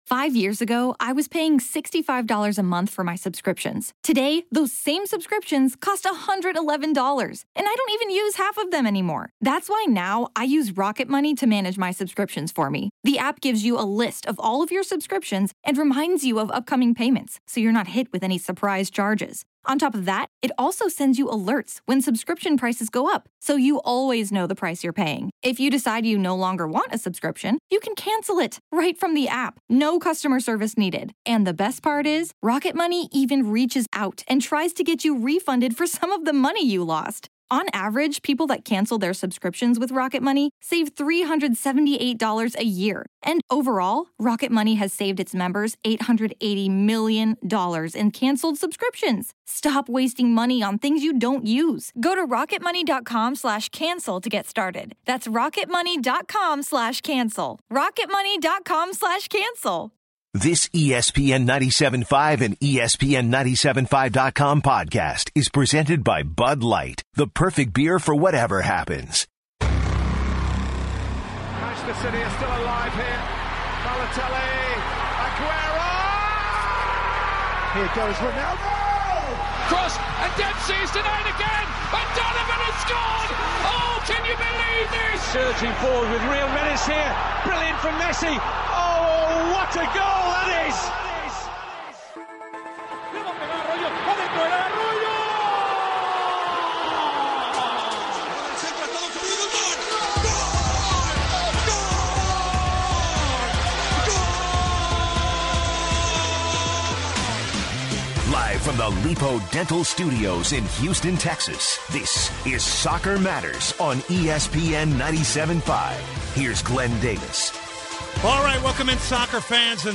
takes calls on Champion League, Premier League and all things international soccer.